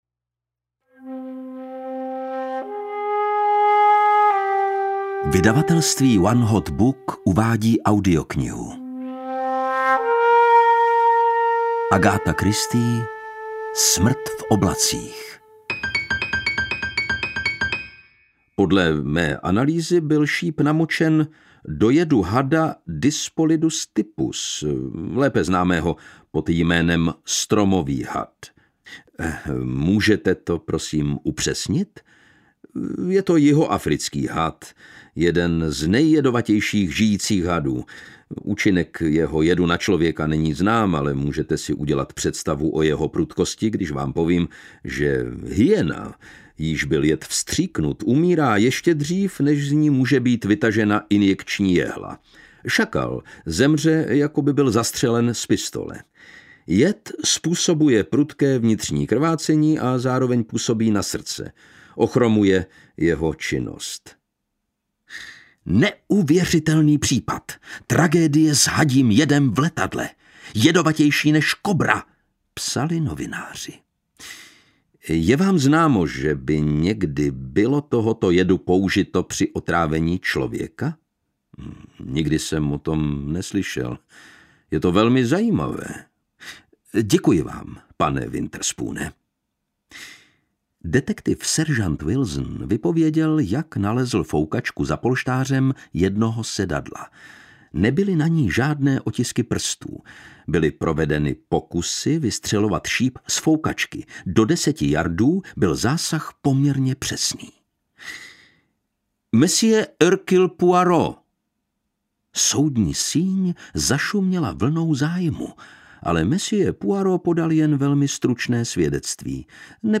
Smrt v oblacích audiokniha
Ukázka z knihy
• InterpretLukáš Hlavica
smrt-v-oblacich-audiokniha